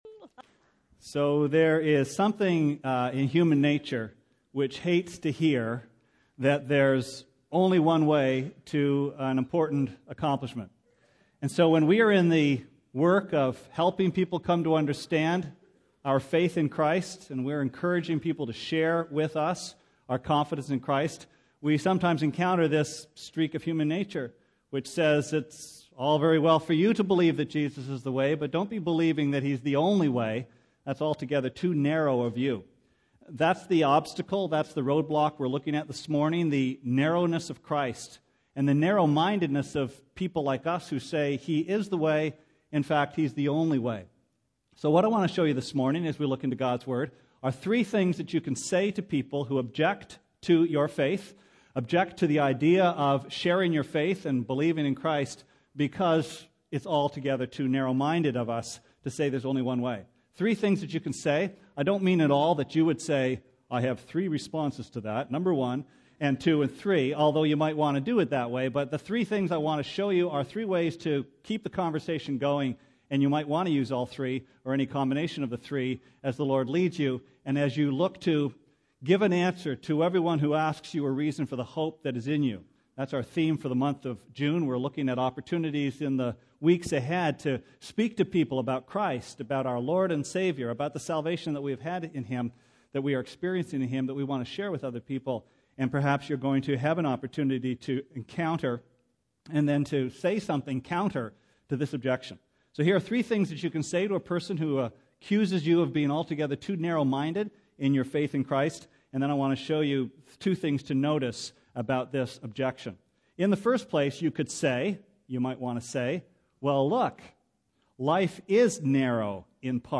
Sermon Archives Jun 1 2008- Roadblocks 1